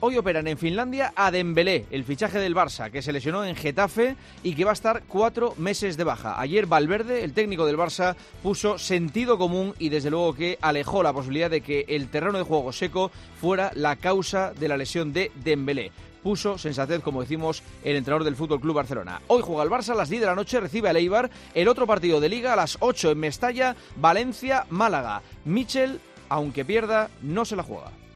El comentario de Juanma Castaño
Dembélé será operado y estará 4 meses de baja, la afición del Barça lo achaca al mal estado del terreno de juego, el técnico culé lo desmiente. Escucha ya el comentario del director de 'El Partidazo de COPE, Juanma Castaño, en 'Herrera en COPE'.